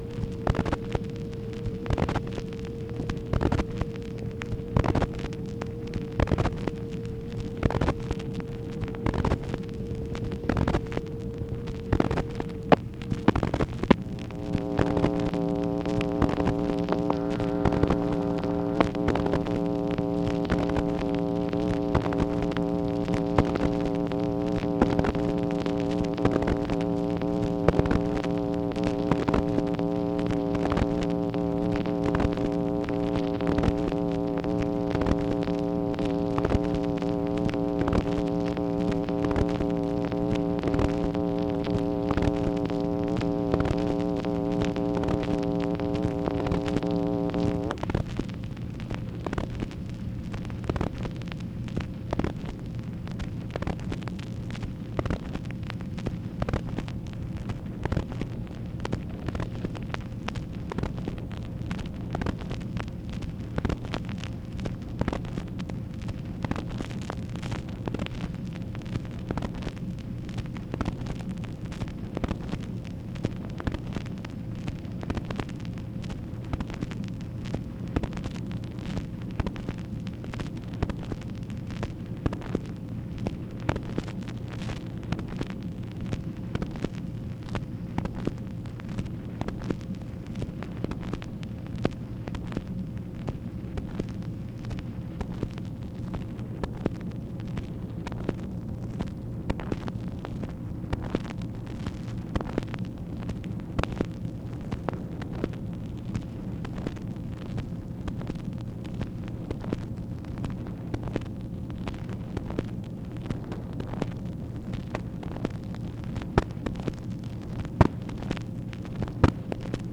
MACHINE NOISE, August 14, 1964